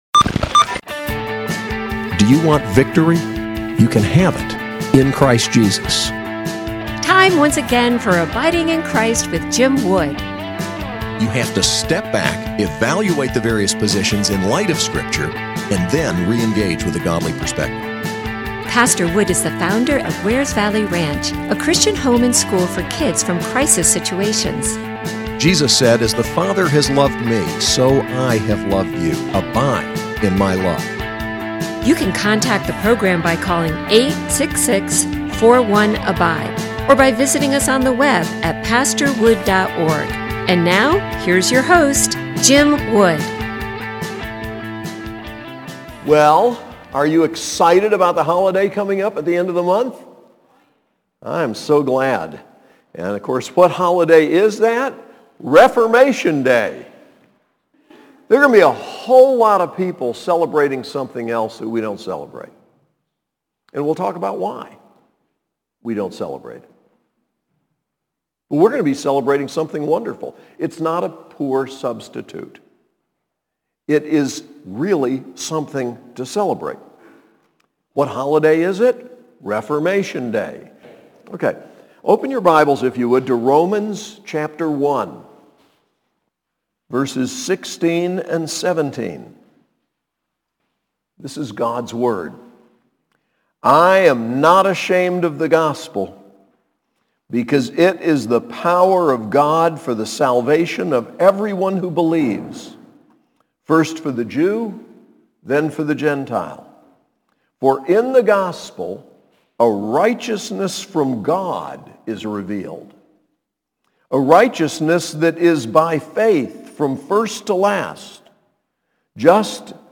SAS Chapel: Reformation, Martin Luther